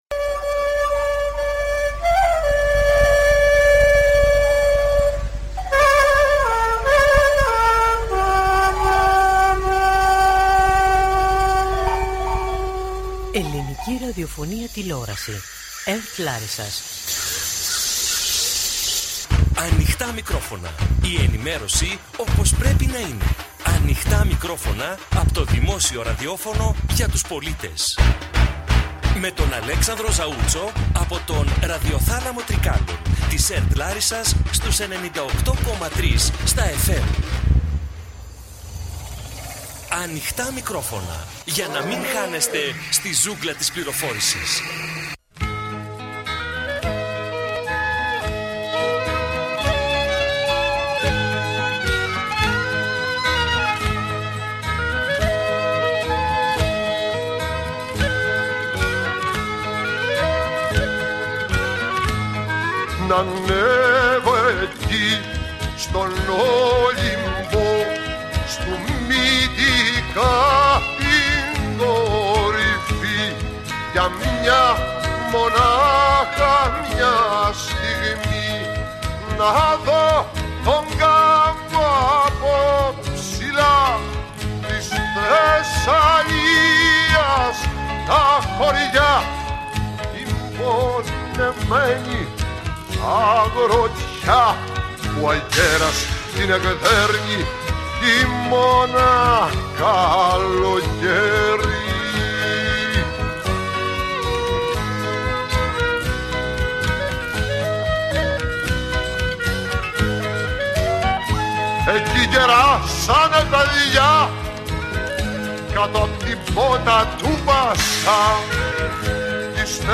Αφιερωμένη στις ελπίδες των αγροτών για μια καλύτερη ζωή, η εκπομπή “Ανοιχτά Μικρόφωνα” , από τον Ραδιοθάλαμο Τρικάλων της ΕΡΤ Λάρισας .